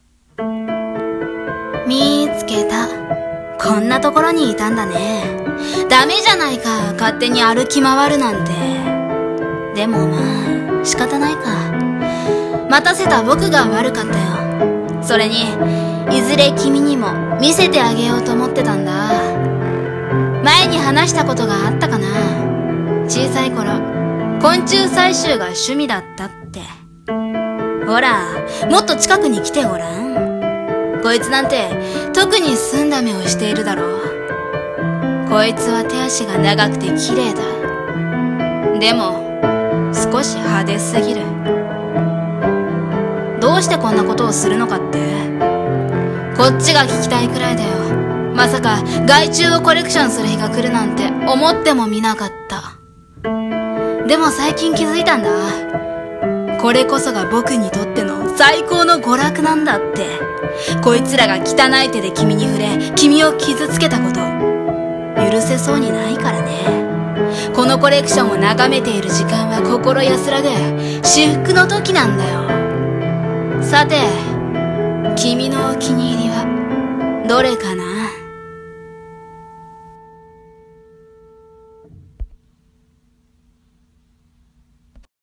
【台本】標本室【一人声劇】
★ピアノ